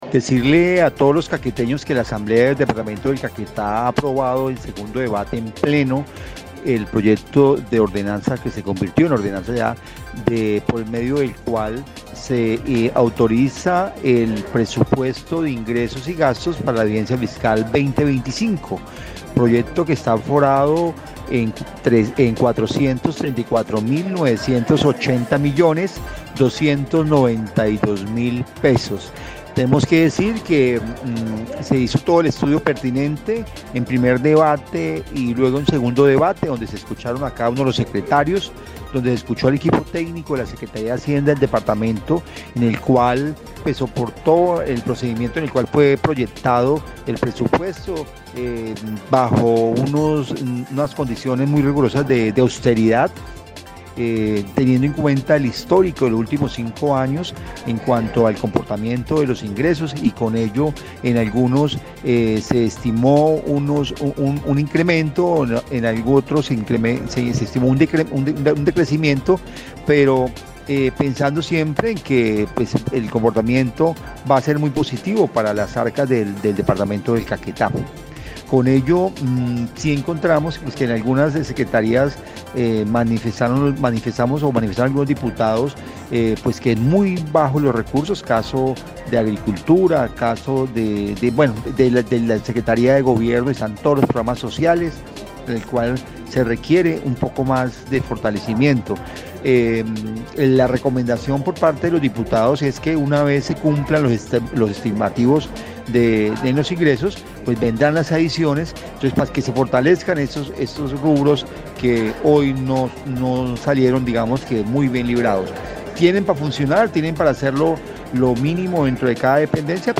Richard Gutiérrez Cruz, diputado por Cambio Radical y actual presidente de la corporación, explicó que la propuesta de presupuesto fue analizada tanto en la comisión económica como en plenaria de la corporación, con algunas recomendaciones de los diferentes diputados.
04_DIPUTADO_RICHARD_GUTIERREZ_PRESUPUESTO.mp3